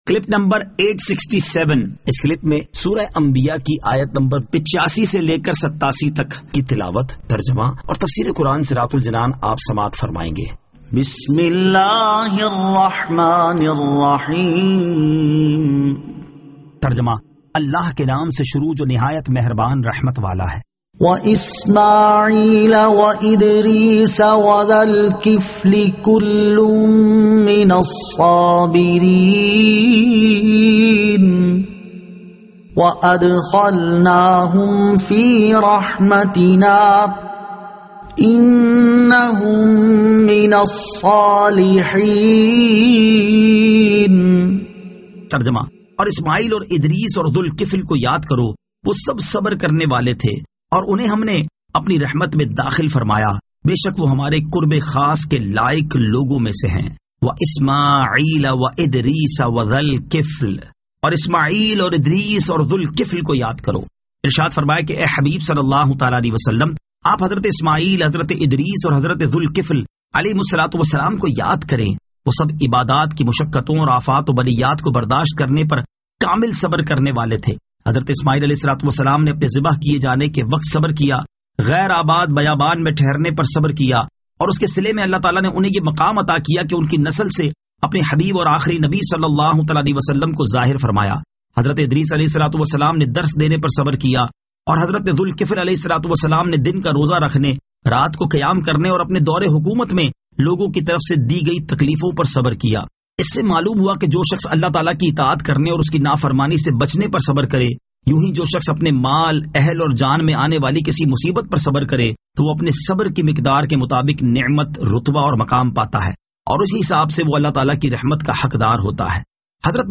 Surah Al-Anbiya 85 To 87 Tilawat , Tarjama , Tafseer